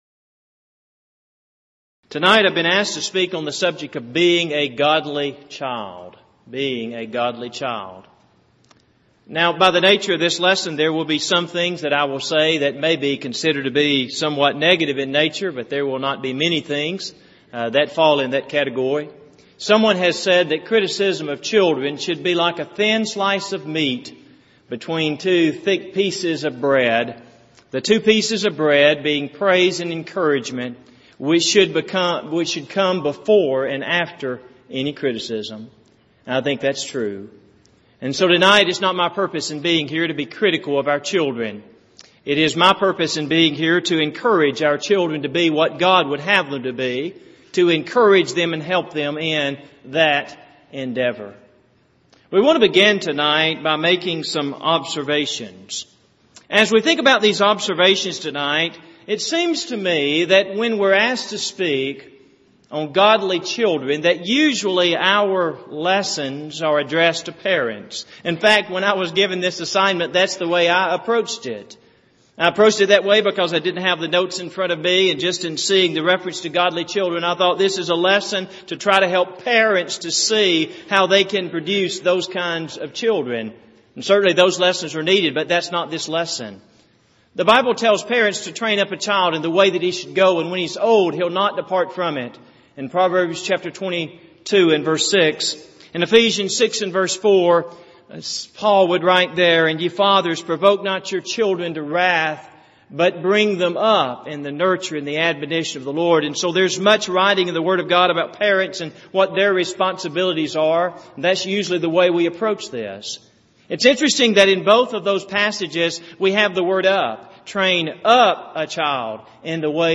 Event: 28th Annual Southwest Lectures
If you would like to order audio or video copies of this lecture, please contact our office and reference asset: 2009Southwest03